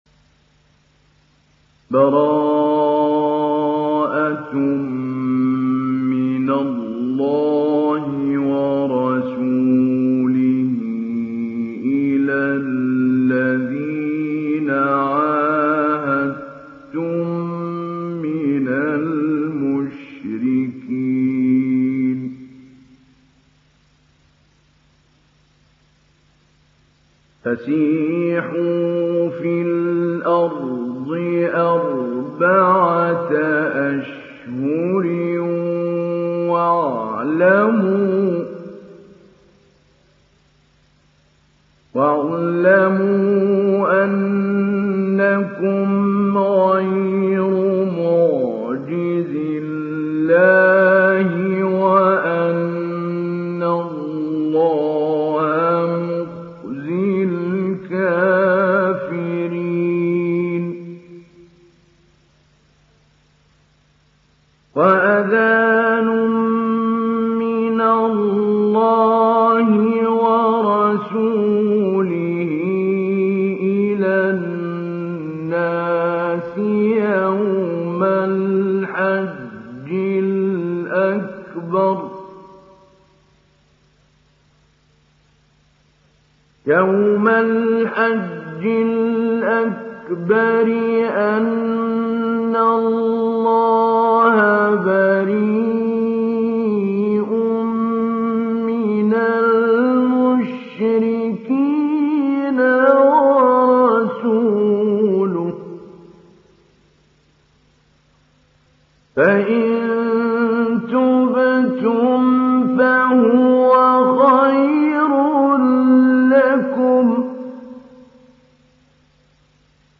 تحميل سورة التوبة mp3 بصوت محمود علي البنا مجود برواية حفص عن عاصم, تحميل استماع القرآن الكريم على الجوال mp3 كاملا بروابط مباشرة وسريعة
تحميل سورة التوبة محمود علي البنا مجود